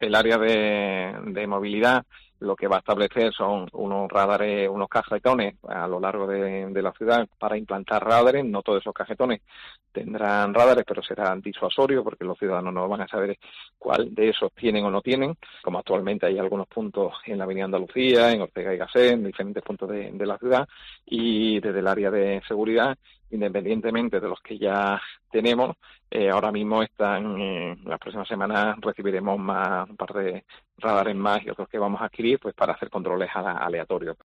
Escucha aquí al concejal de Seguridad del Ayuntamiento de Málaga, Avelino Barrionuevo.